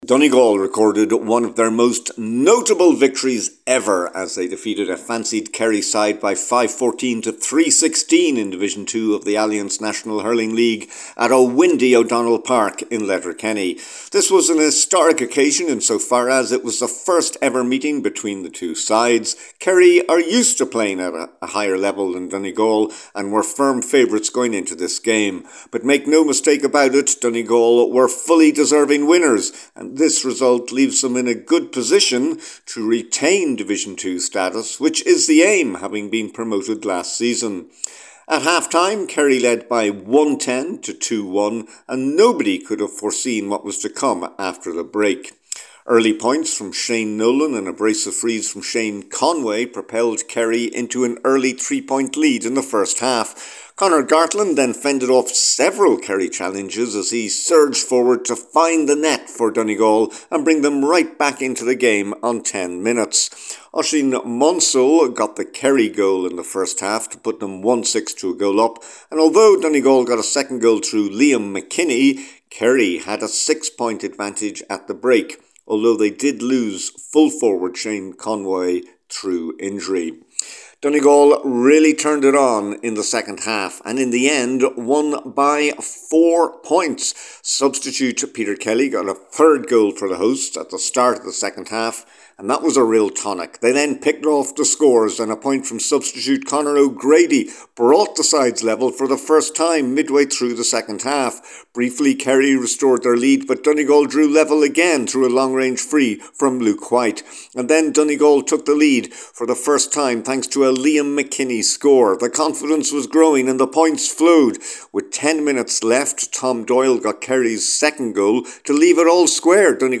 from O’Donnell Park…